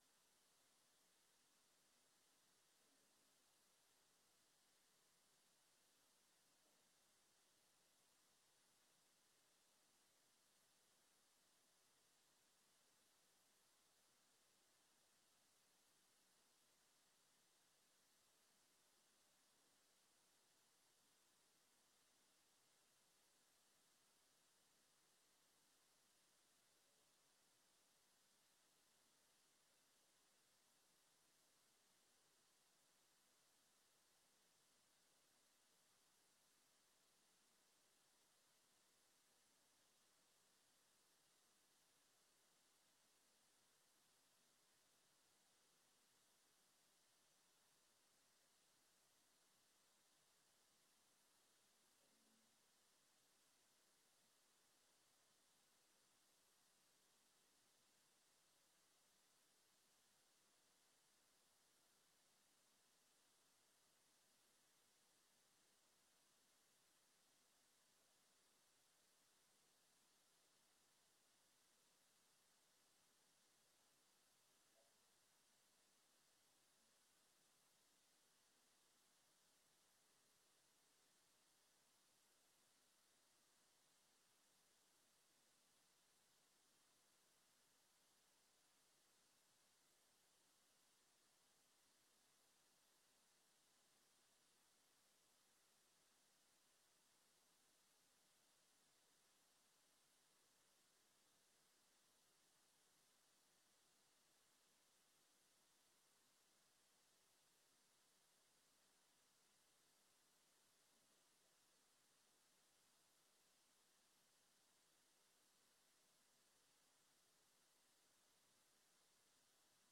Download de volledige audio van deze vergadering
Locatie: Razende Bol